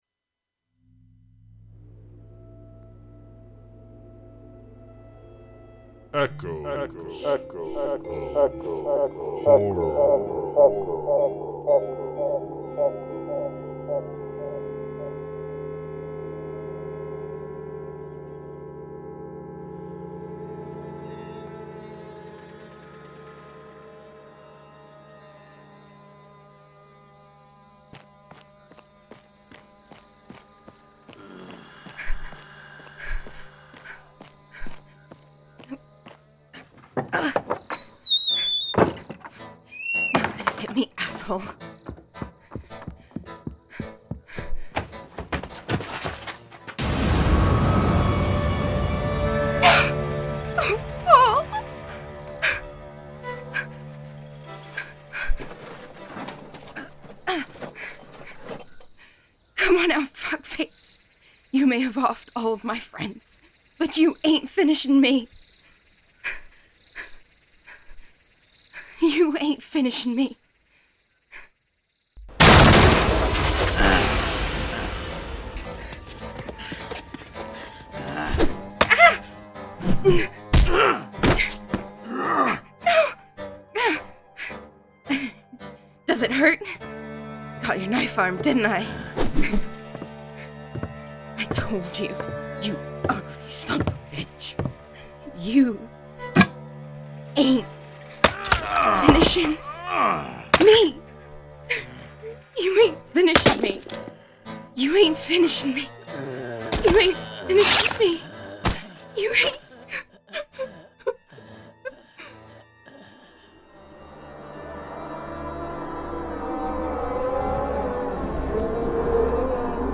drama